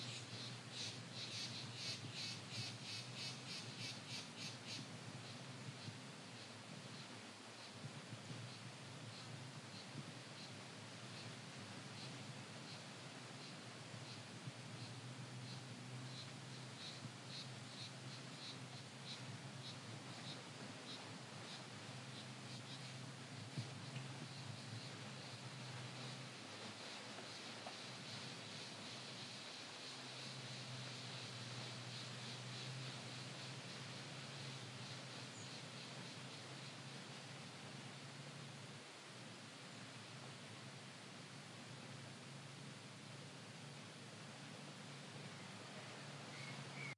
Brazil selva sounds " Mineiros Brasil selva 7.12.17 afternoon crickets stop
描述：雨季巴西农场的下午昆虫：蝉和蟋蟀（？）。
标签： 性质 现场录音 巴西 昆虫
声道立体声